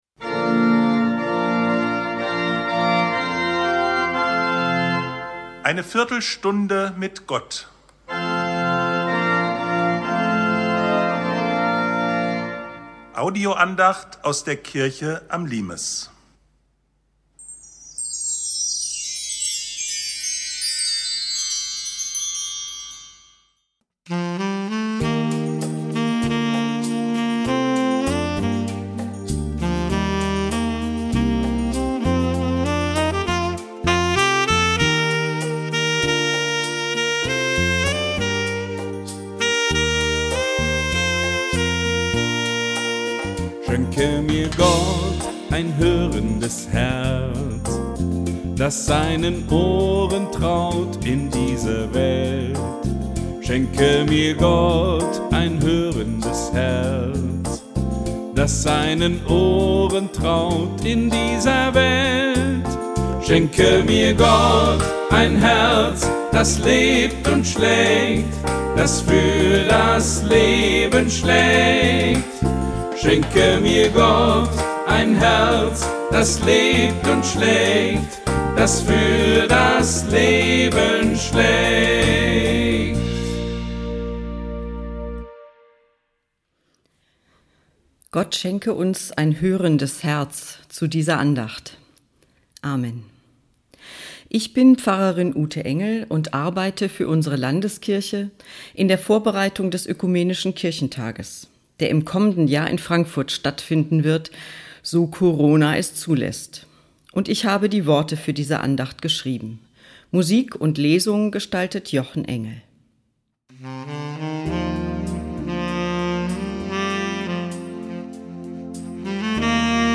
Audioandacht zum Sonntag “Exaudi”, 24.5.2020